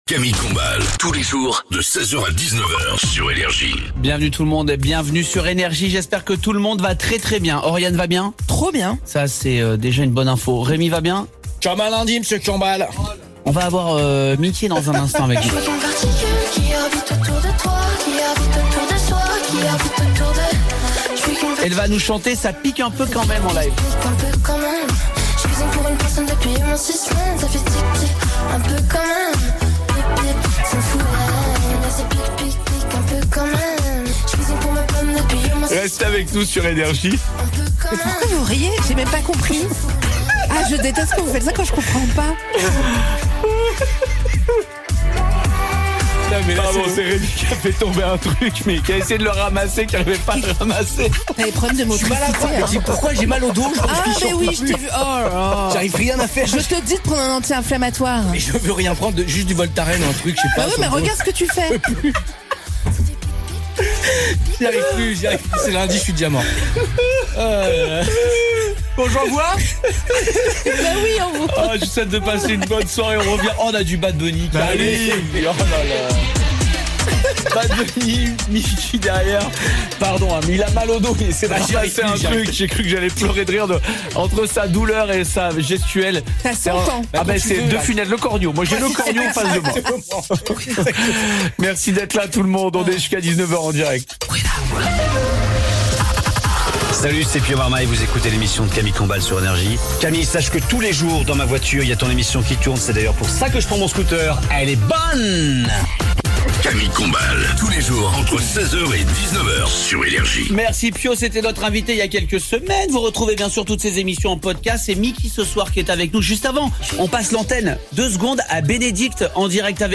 Le 18-19h : Miki chante en live et nous nous dévoile tout sur sa tournée, sa musique, ses textes